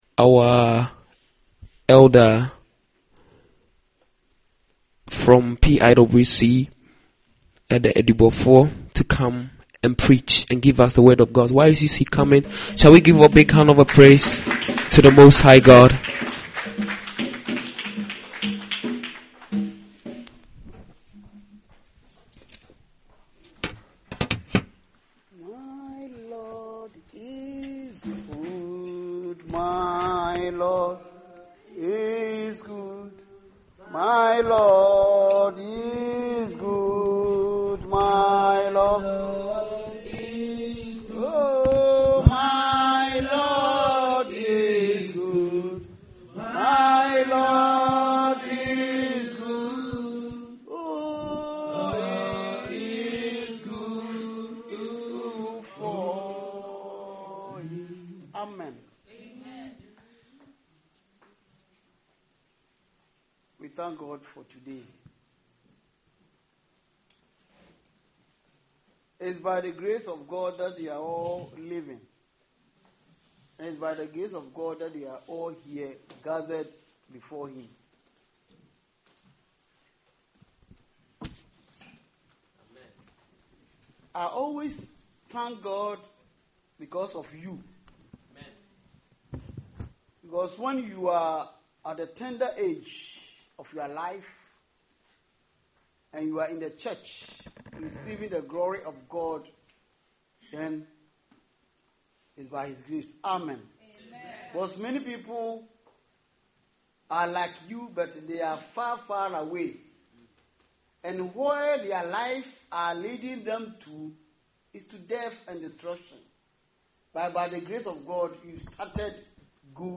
Dutch Assembly